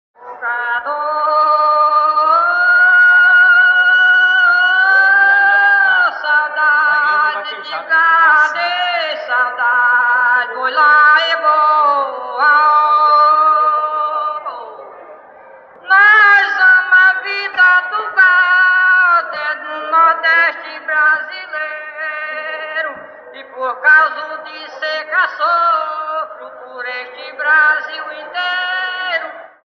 Aboio em verso
Aboio cantado por um ou mais intérpretes com letras que têm como tema a vida e o trabalho do boiadeiro.
Termo Genérico Aboio Termos Associados Poesia popular Objetos Digitais Aboio em verso autor: - Gupo de vaqueiros de Canindé., data: 1982 Som CNFCP acervo (465.2KB)
aboioemverso.mp3